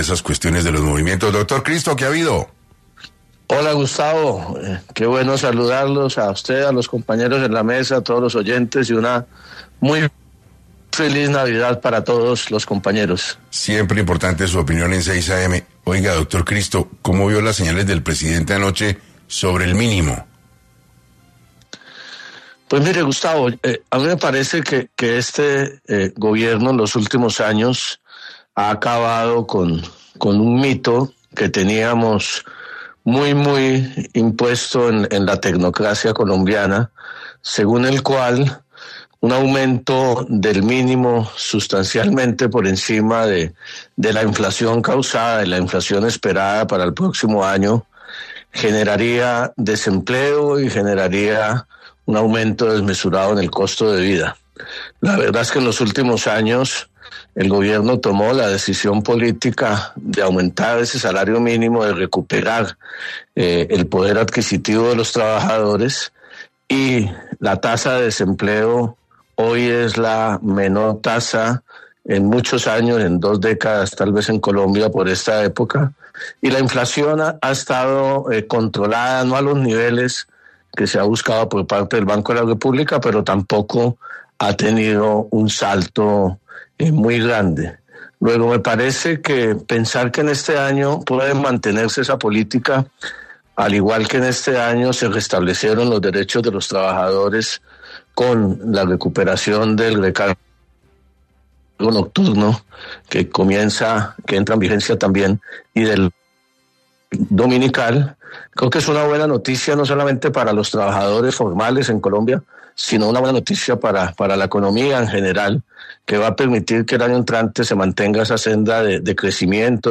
En entrevista para 6AM, Juan Fernando Cristo, exministro del Interior y precandidato presidencial, se refirió al decreto de emergencia económica señalado por el presidente Gustavo Petro tras el hundimiento de la reforma tributaria, así como la Asamblea Constituyente que busca promover el Gobierno.